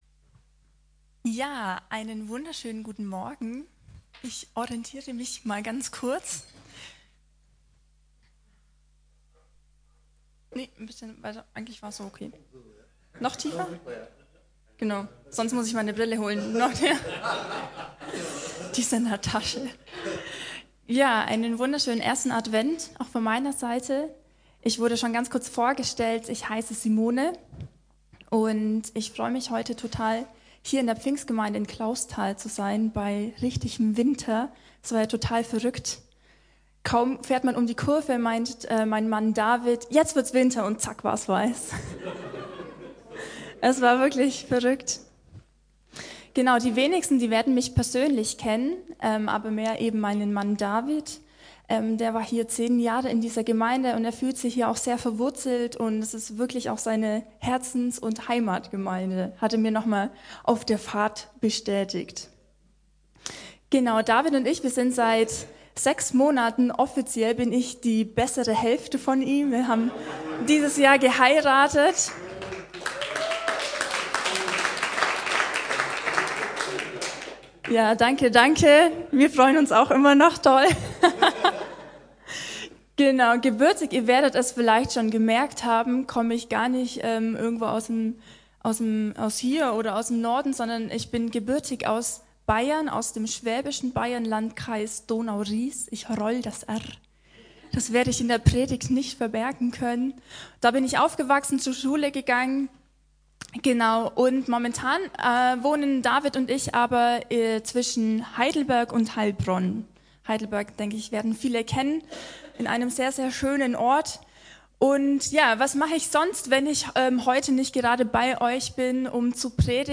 Kirche am Ostbahnhof Navigation Infos Über uns…
Predigten